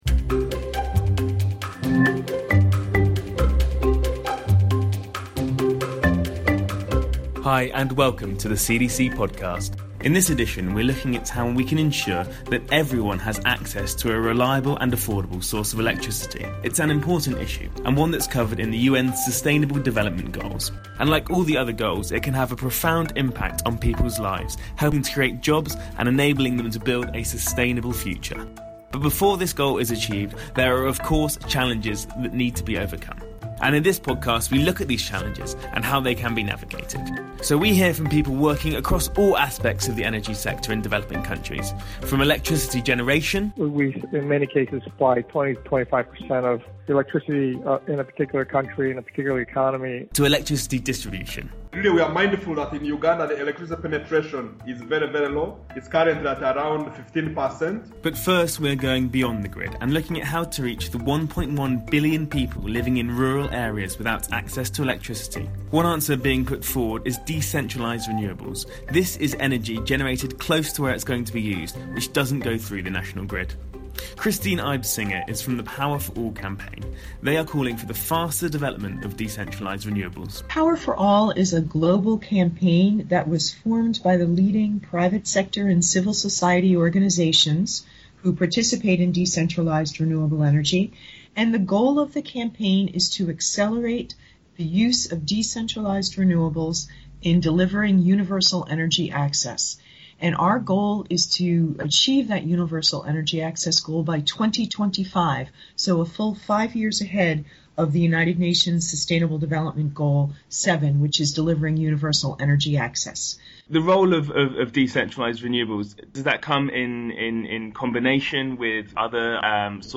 In this podcast, we hear from people working in various areas of the energy sector in developing countries to find out how these challenges can be navigated.